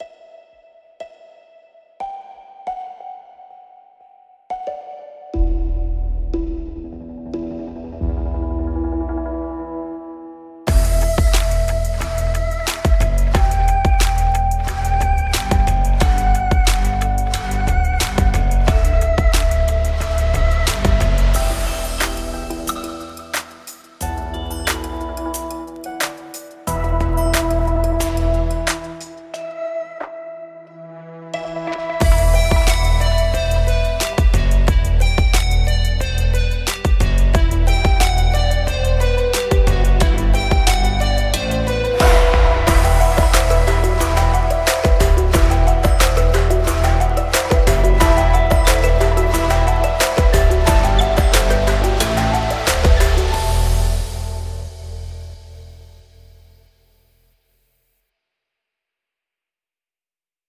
Artistry Audio Staccato 是一种基于 Kontakt 的音色库，它提供了一种短促的合成器运动引擎，可以用来创造独特的拨弦、键盘、合成器等声音。
Artistry Audio Staccato 适合用于电影、电子、低保真、下行调、有机和声音设计等风格的音乐创作。
Artistry Audio Staccato 的核心功能是 GLOW 引擎，它可以通过旋钮将短促的声音转变为动态的垫音，并且可以调节速度和动态范围。